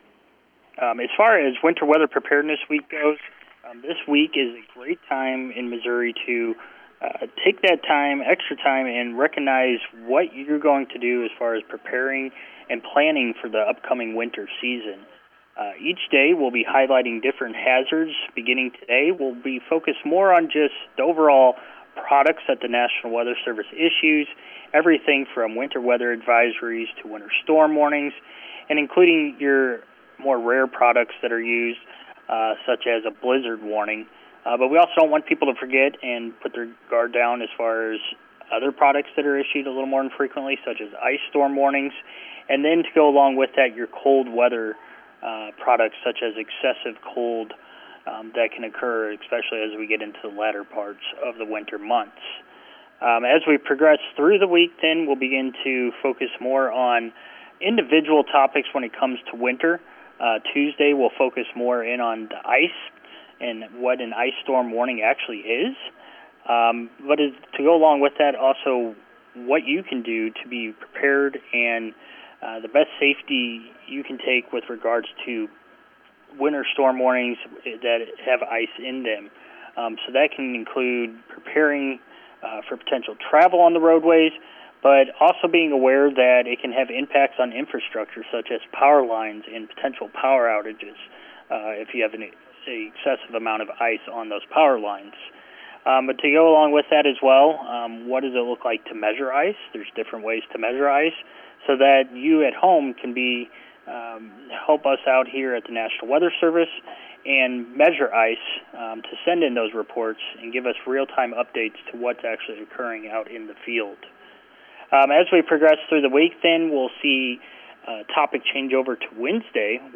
The National Weather Service’s Meteorologist